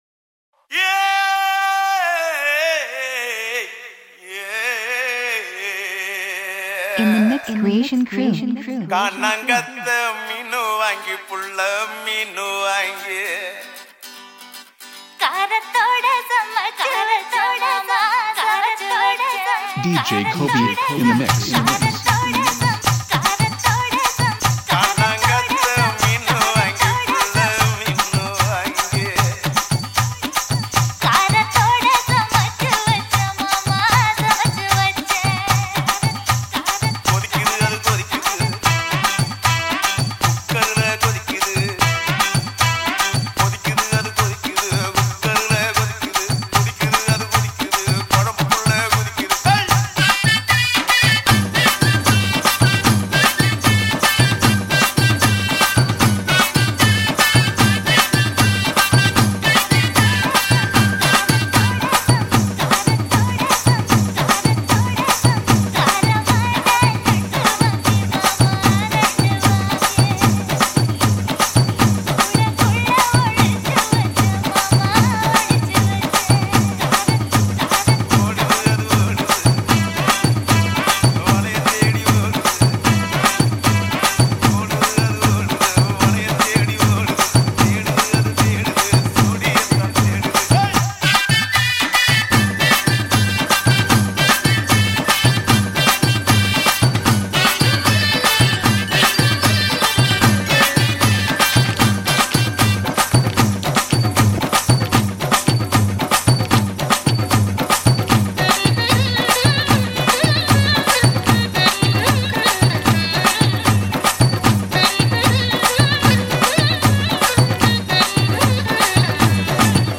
high quality remix